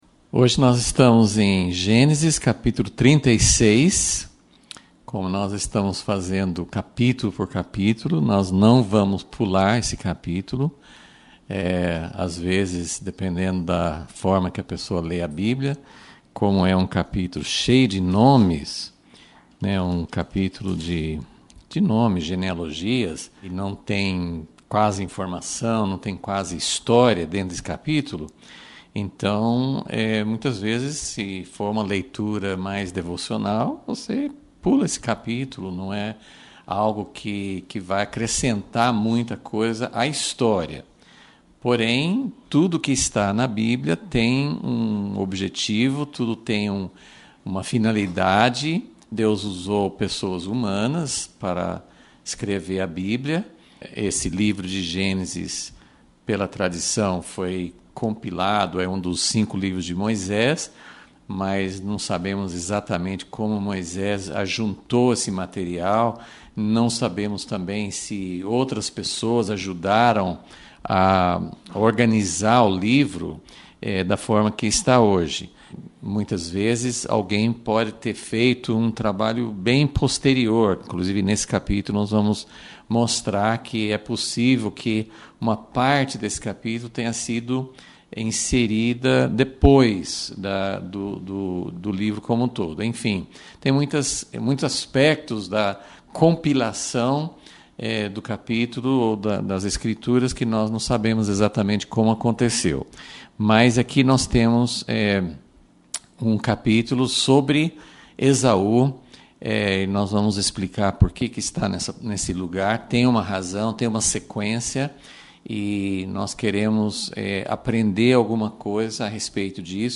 Aula 101 - Gênesis - Esaú e seus descendentes — Impacto Publicações